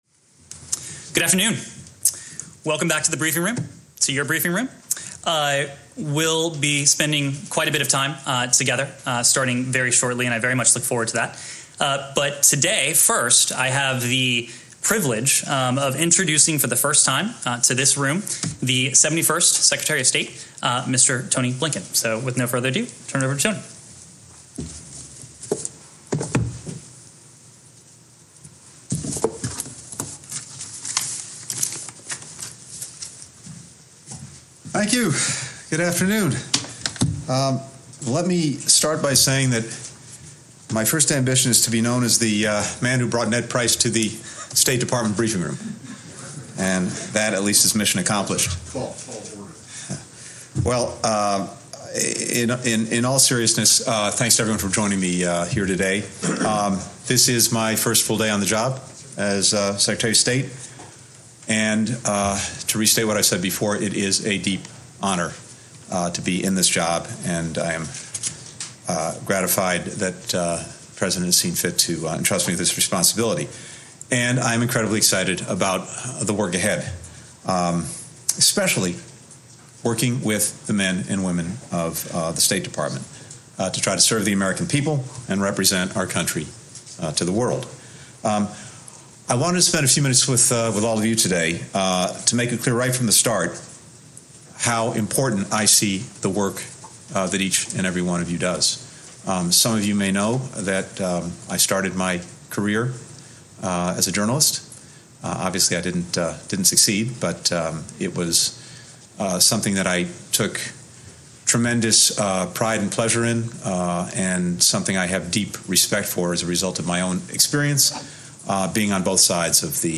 Antony Blinken - First State Department Briefing (text-audio-video)